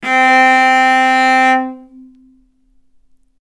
vc-C4-ff.AIF